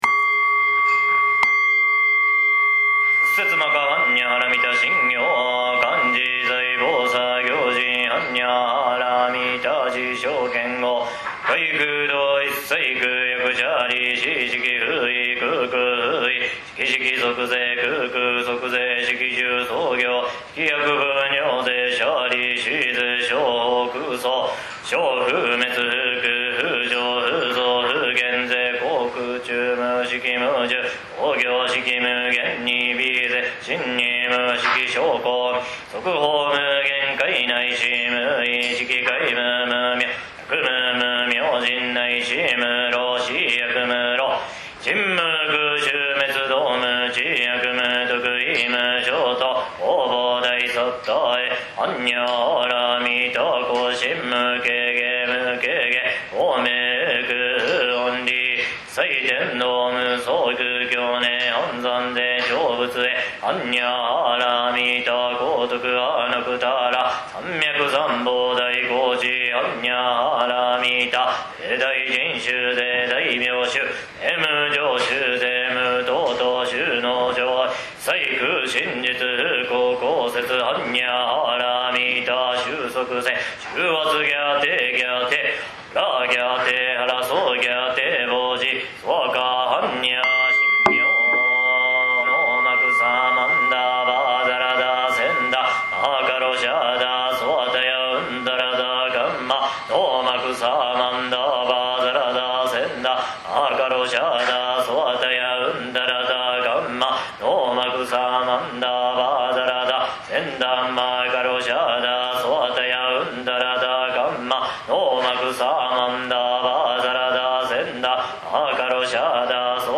龍泉寺の般若心経・不動明王・光明真言 音声データ